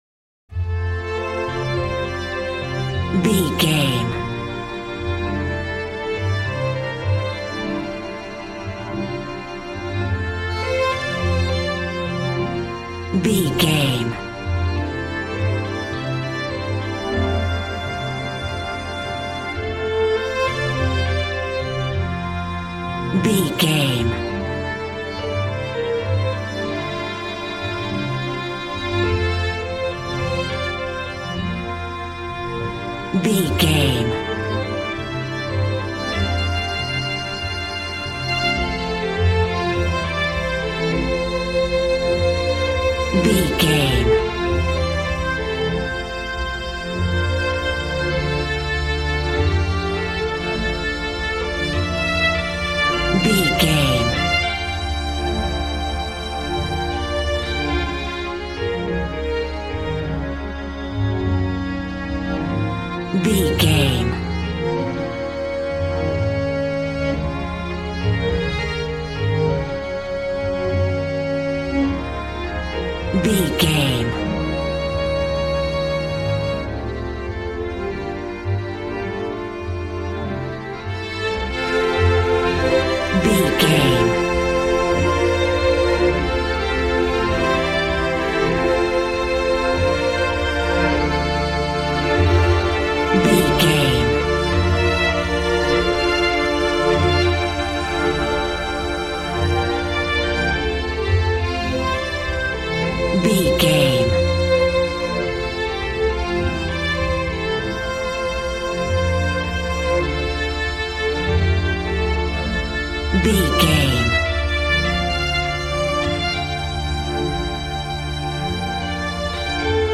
Ionian/Major
joyful
conga
80s